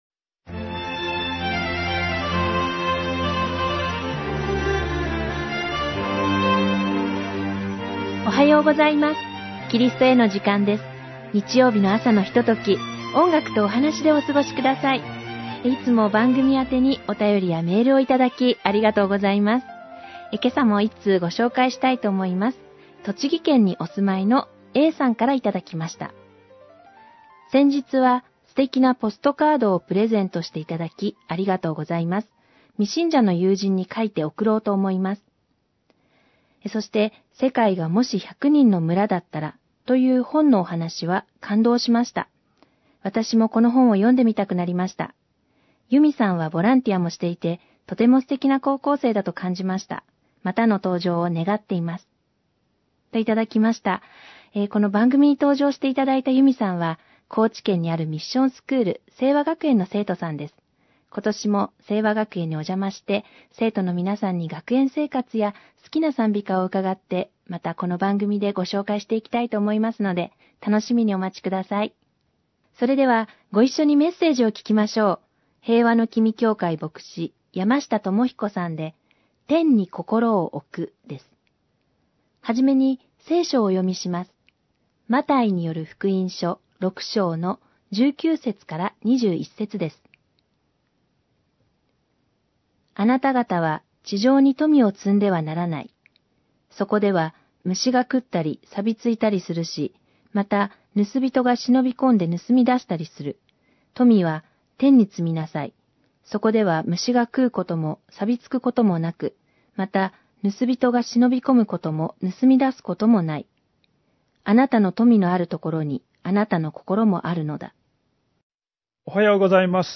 メッセージ： 天に心を置く
※ホームページでは音楽著作権の関係上、一部をカットして放送しています。